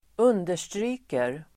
Uttal: [²'un:der_stry:ker]